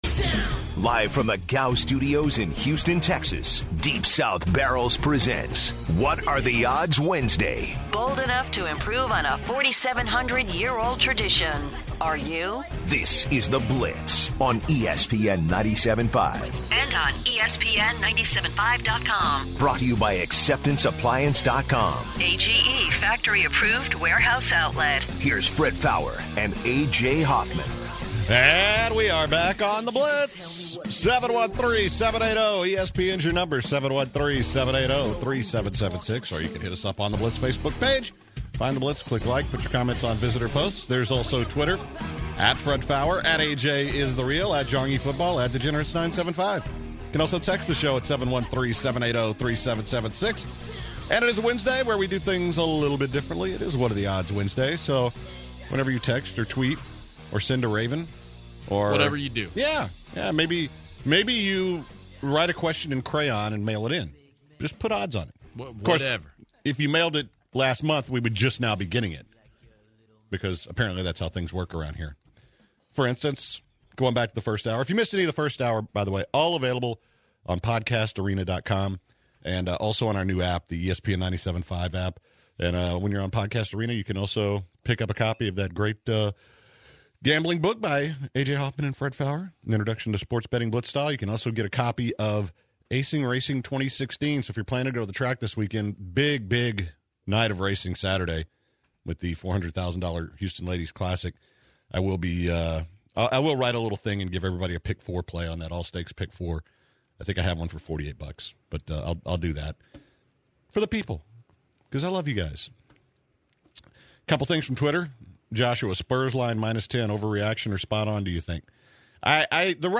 In hour 2 of The Blitz, the guys take more “What Are the Odds” calls from listeners and discuss tops ranging from Peyton Manning, ratings of the latest NFL games and what they think the upcoming Superbowl’s ratings will be, and scholarships.